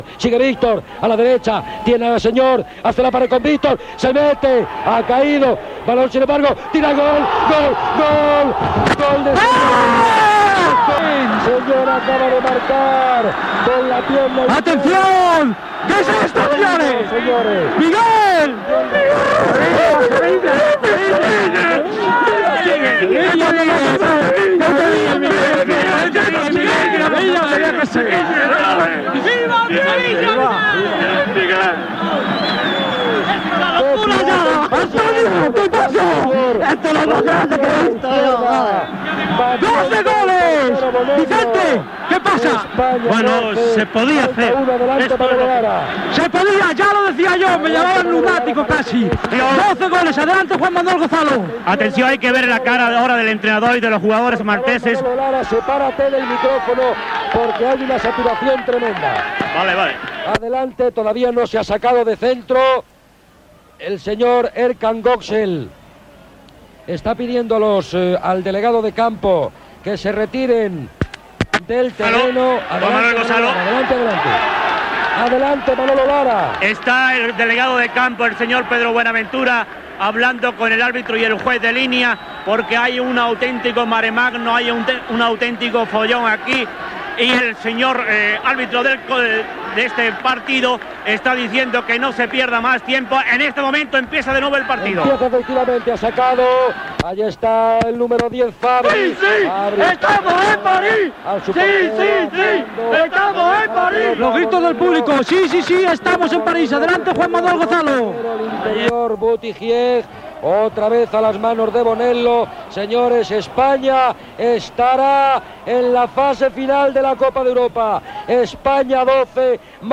Narració del gol de Señor en el partit de futbol masculí Espanya Malta que suposava el 12 a 1 i la classificació d'Espanya per a l'Eurocopa de França. Ambient a la banqueta espanyola.
Esportiu